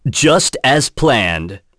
Roman-Vox_Victory.wav